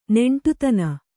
♪ neṇṭutana